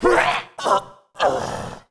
Sound / sound / monster / greenfrog_soldier / dead_1.wav